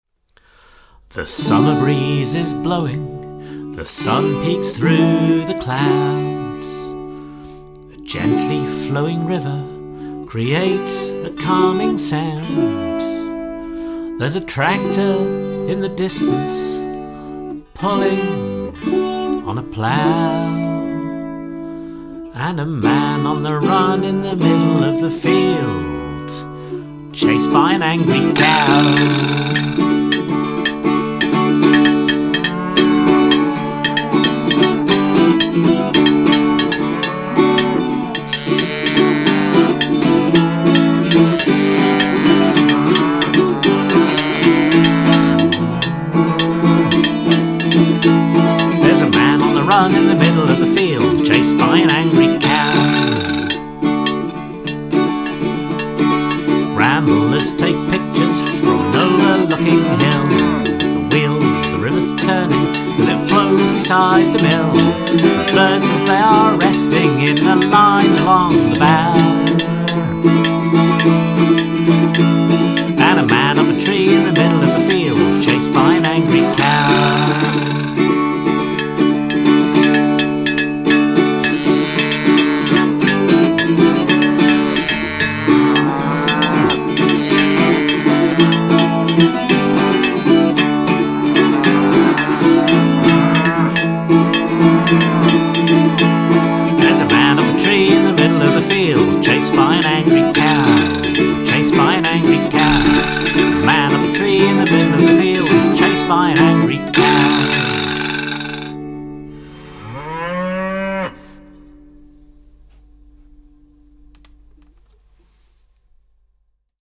There's also lots of cowbell fun, of course, and a clack-badoing (some call it a vibraslap, but not me because I am a pro.)
It is thankfully quite short, but maybe not quite short enough.
Masterful clack-badoing, and the cowbell part is delivered with gusto!
The melody is embedded in my skull, so catchy.
The cow sounds add, but you let that cowbell shine - as it should.
Vibraslap entrance 100% correct, as are the cow-sound interludes, and that 8-string sounds lovely.
I think of this as Cowcore v2.0. Excellent cowbell break and cow moo's. I don't know if this genre can surpass this point.
I think this is the perfect folksy style to write a song about the “man chased by cow” breaking moos 🙃